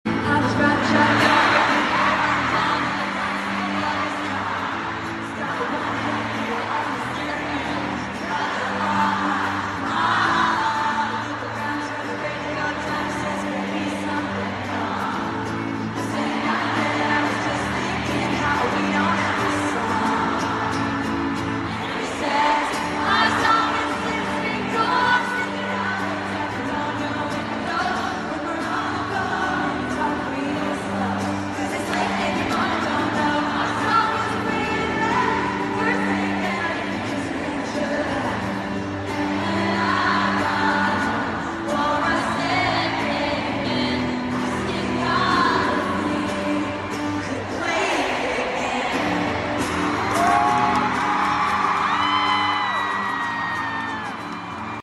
was the surprise song
the crowd went nuts